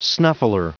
Prononciation du mot snuffler en anglais (fichier audio)
Prononciation du mot : snuffler